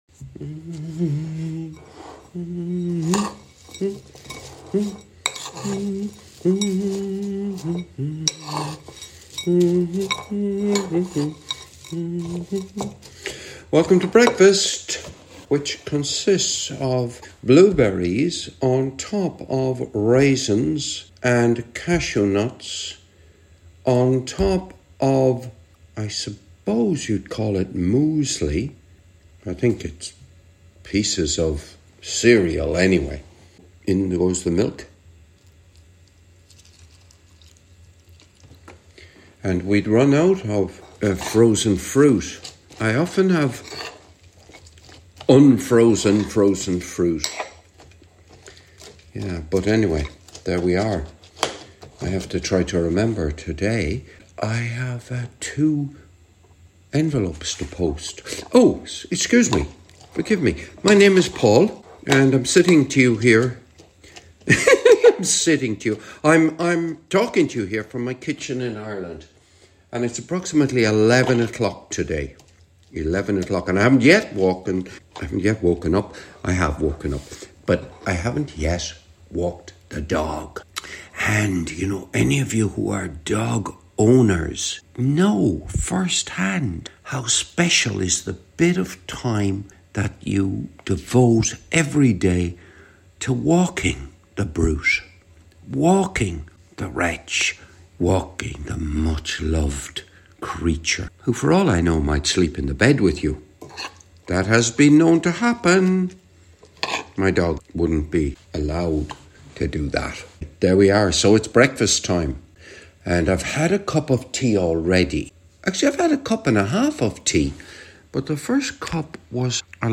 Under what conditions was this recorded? This episode was recorded in my kitchen on the morning of 14th November 2024